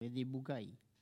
Elle provient de Bouin.
Locution ( parler, expression, langue,... )